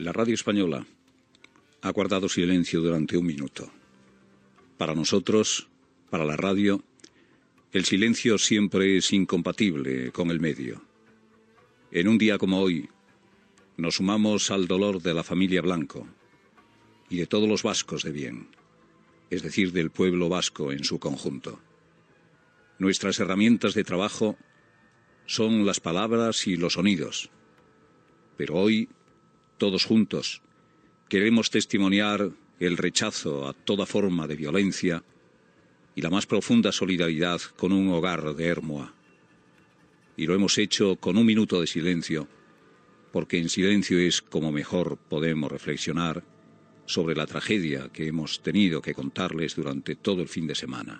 Comentarí després del minut de silenci de la ràdio espanyola després de l'assassinat del polític Miguel Ángel Blanco.
Info-entreteniment
FM